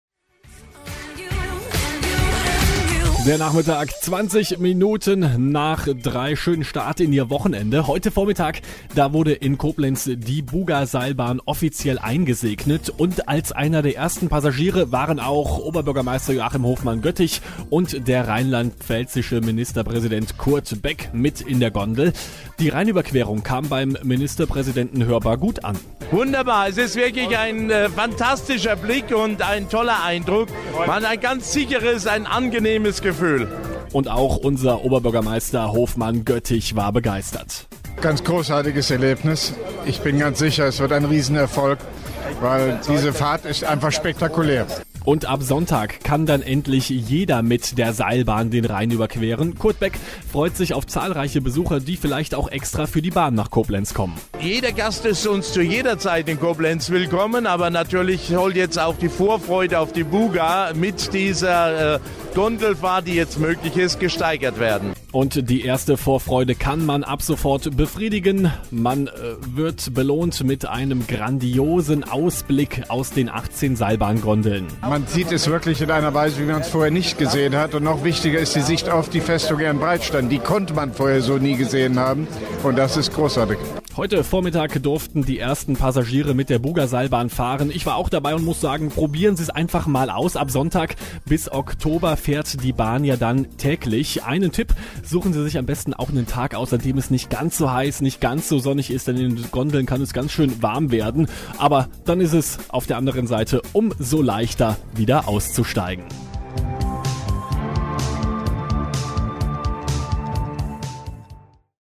Mit Statements des rheinland-pfälzischen Ministerpräsidenten Kurt Beck und dem Koblenzer OB Hofmann-Göttig